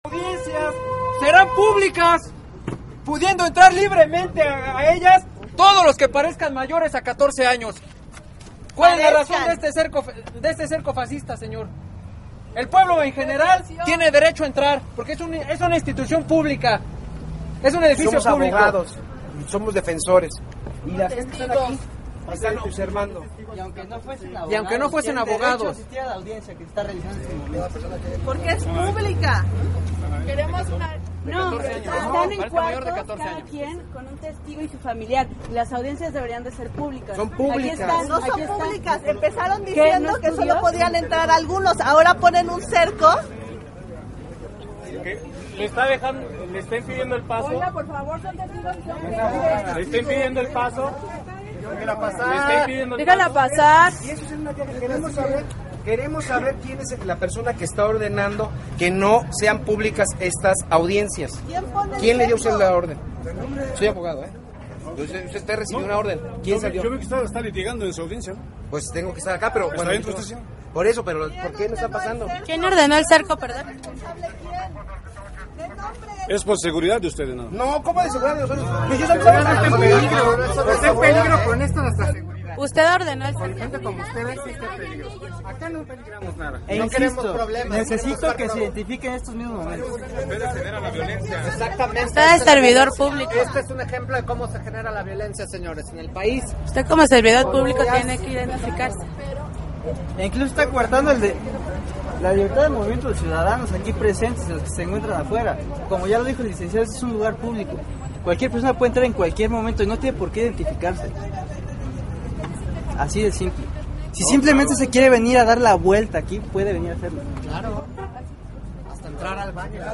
Audio cuando impedian el paso